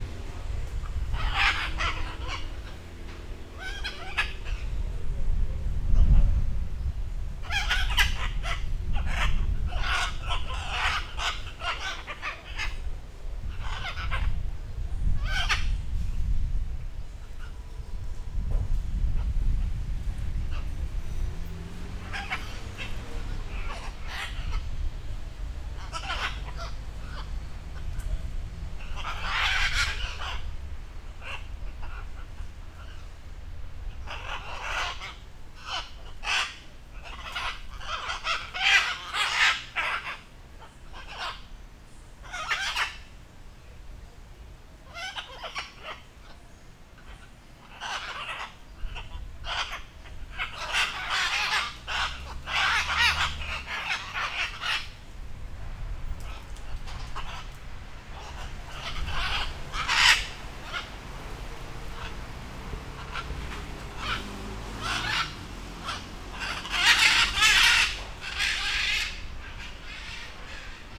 Las lapas de MI Finca GUANACASTE